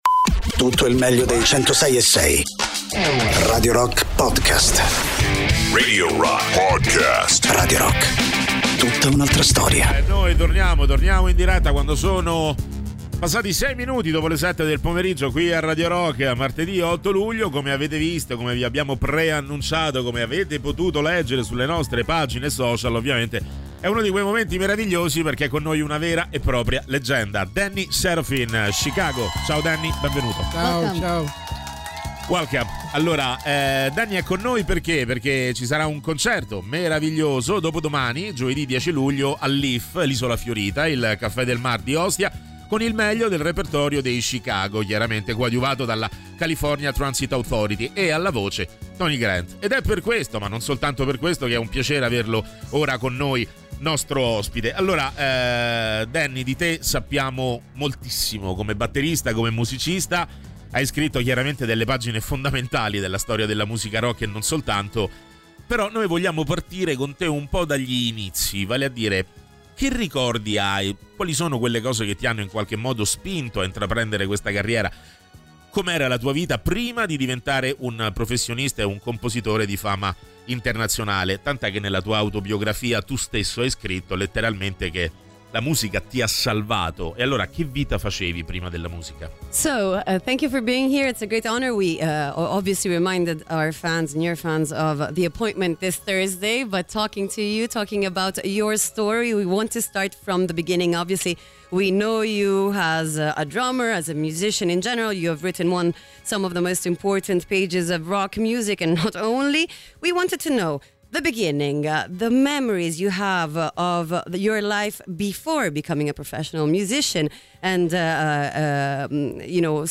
Interviste: Danny Seraphine (09-07-25)